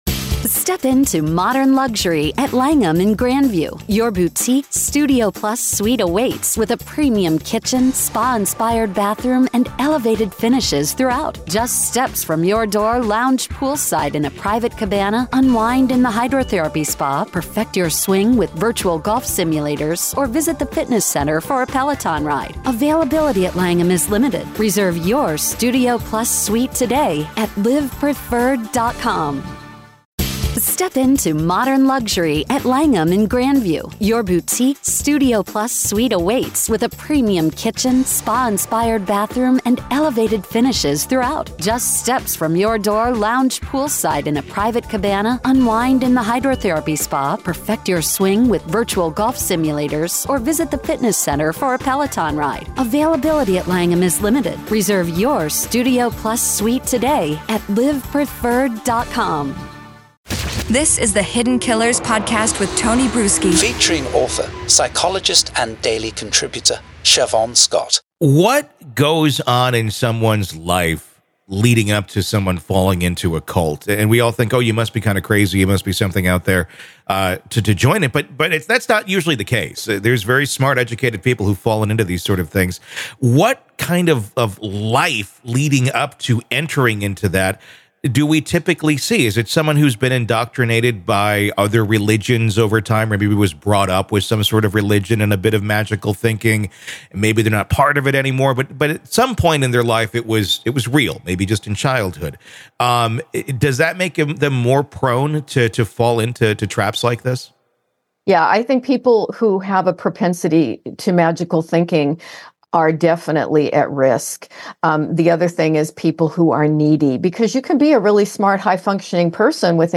The discussion raises questions about the nature of evil and the psychological justification for heinous acts under the guise of religious or cult beliefs. Main Points of the Conversation - Characteristics that make individuals susceptible to cults, such as emotional neediness and a background in magical thinking.